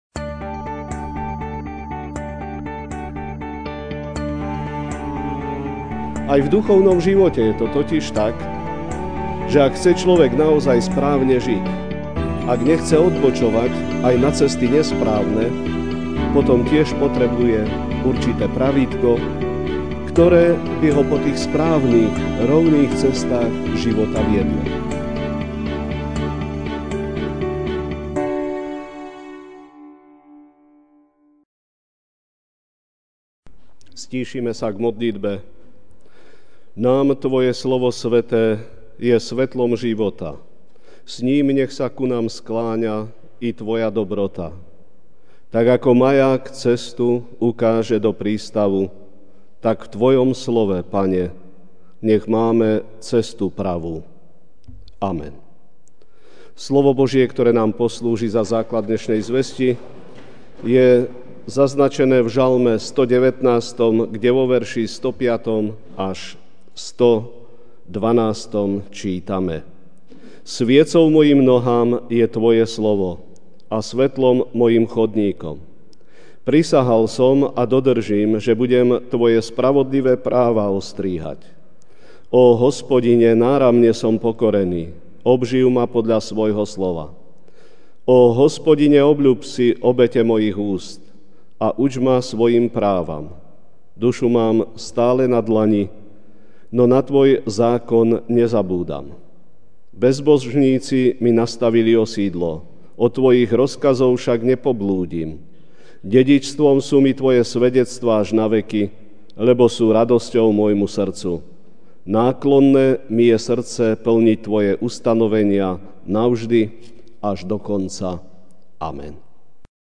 Večerná kázeň: Pravítko života (Žalm 119, 105-112) Sviecou mojim nohám je Tvoje slovo a svetlo mojim chodníkom.